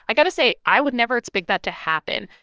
conversational-tts telephony text-to-speech
Pheme generates a variety of conversational voices in 16 kHz for phone-call applications